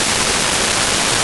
static.ogg